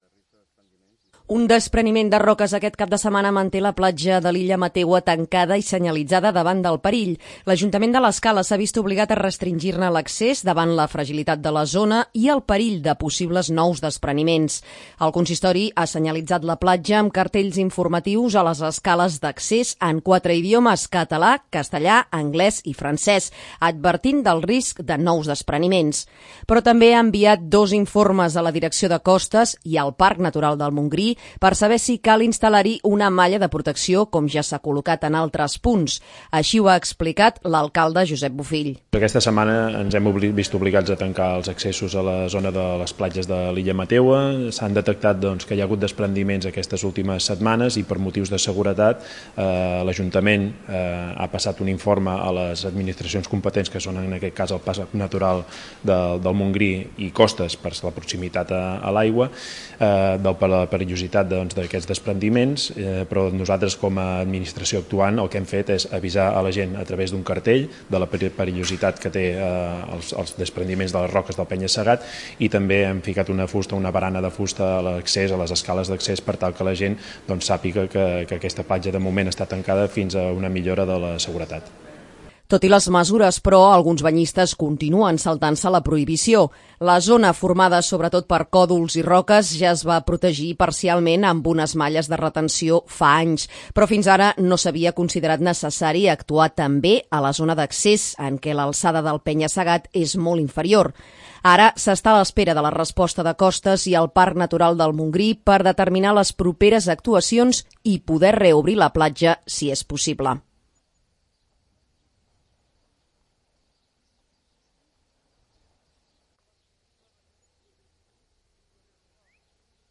Així ho ha explicat l'alcalde Josep Bofiill